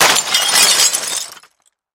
На этой странице собраны звуки, связанные с зеркалами: от едва уловимых отражений до резких ударов.
Звук разбитого зеркала